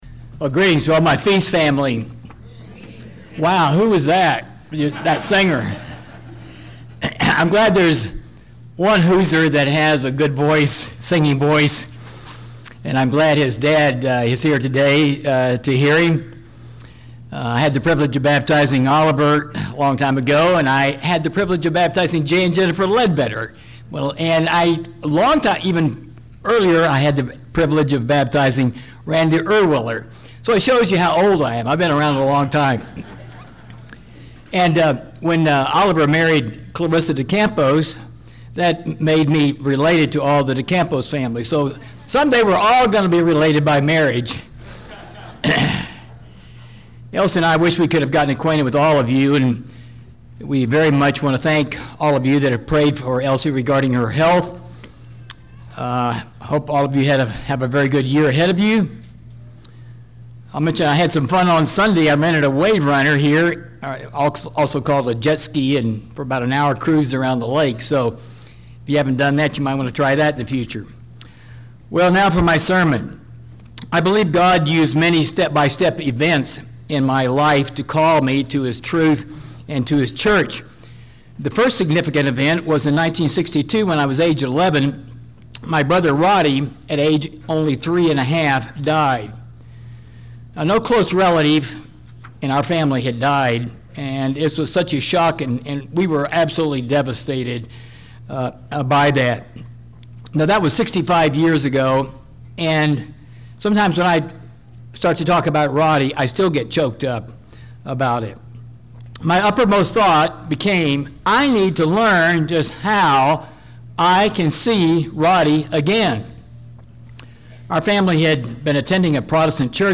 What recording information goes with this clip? This sermon was given at the Lake Texoma, Texas 2017 Feast site.